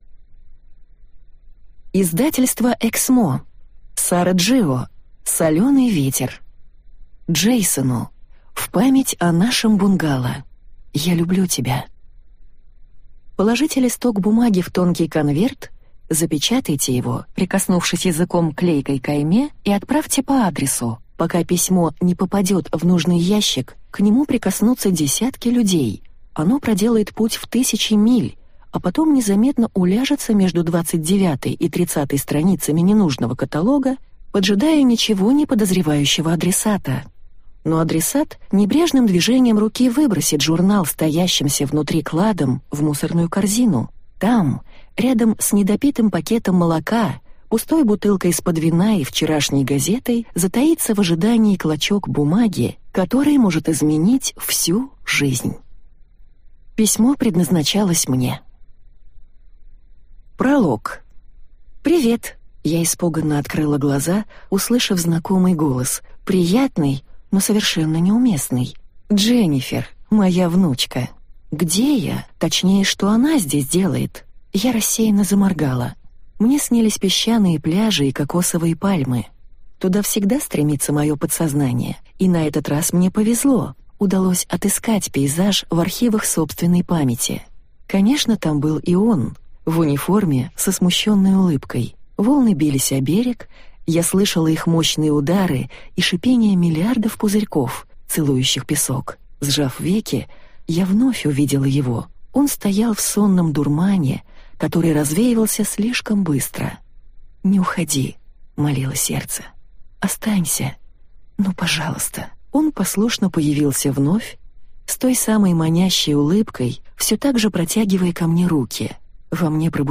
Аудиокнига Соленый ветер - купить, скачать и слушать онлайн | КнигоПоиск